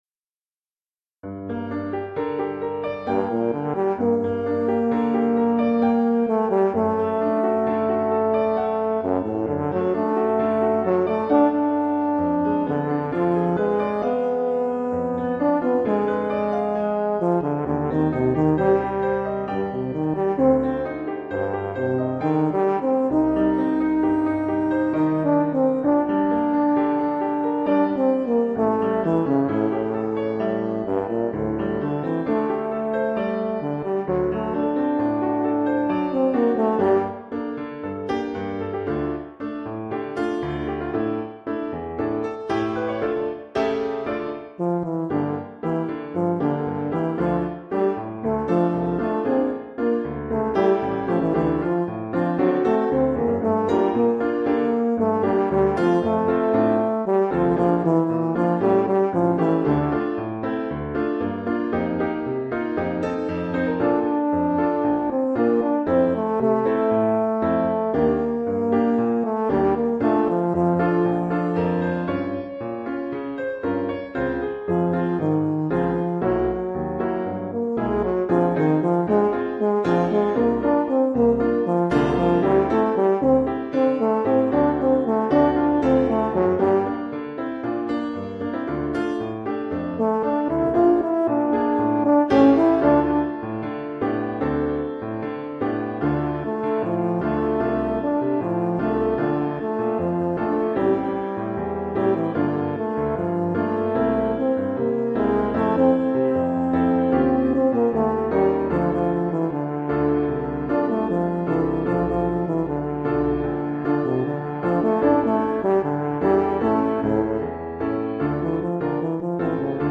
Oeuvre pour saxhorn basse /
euphonium et piano.